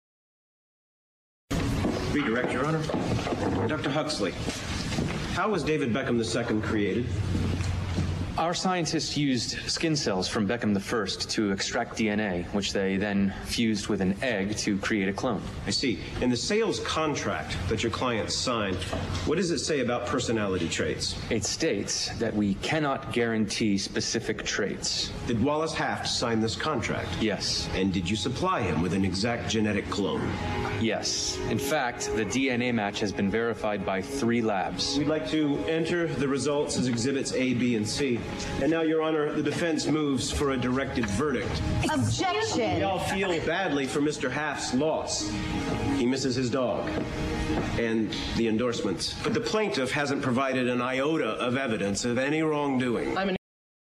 在线英语听力室影视剧中的职场美语 第122期:合同纠纷的听力文件下载,《影视中的职场美语》收录了工作沟通，办公室生活，商务贸易等方面的情景对话。